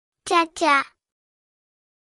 \Cah-Cah\